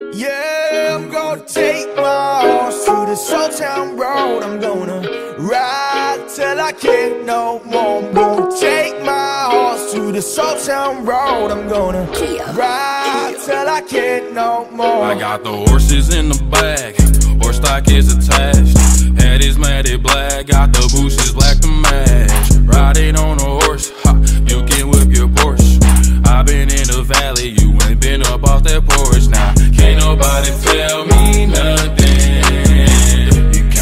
Rap - Hip Hop